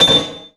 SI2 METAL0FR.wav